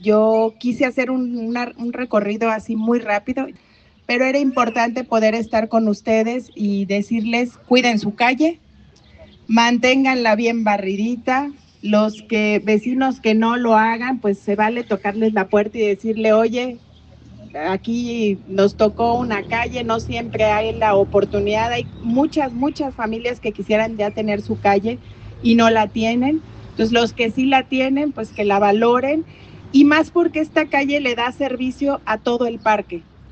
Lorena Alfaro García, presidenta de Irapuato